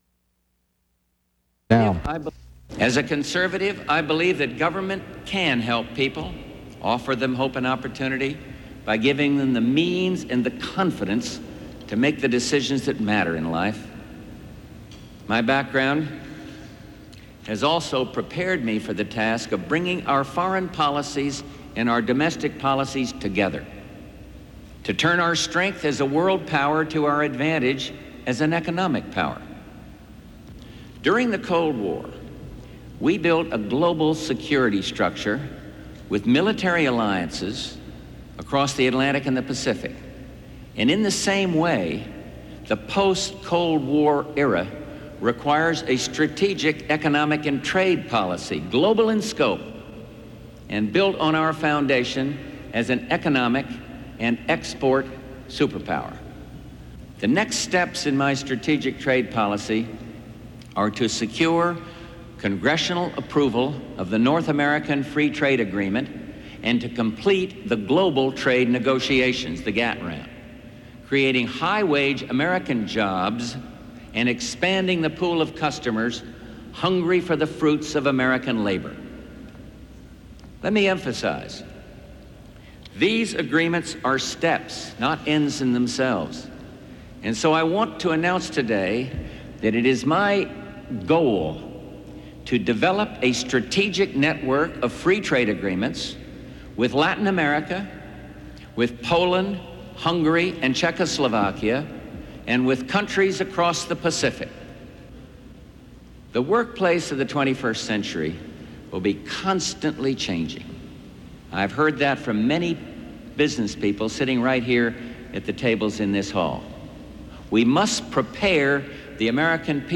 Excerpt of George Bush announcing his new economic agenda in a speech to the Detroit Economic Club